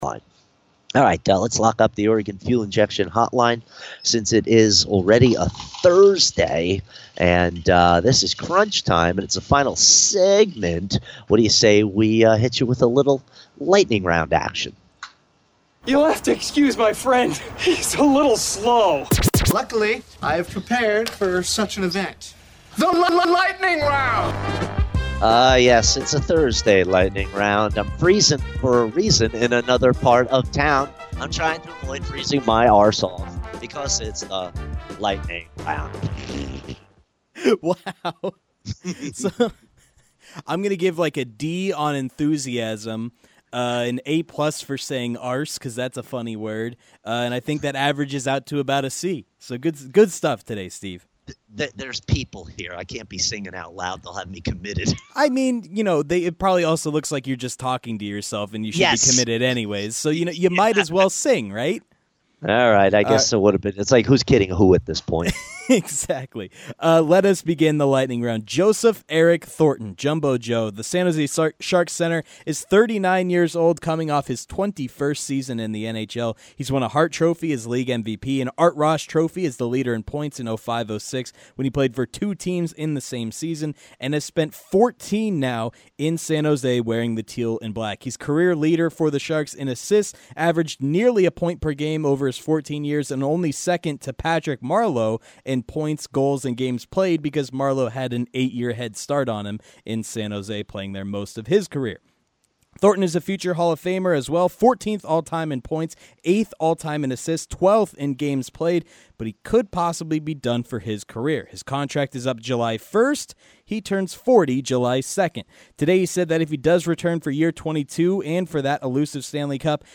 rapid-fire style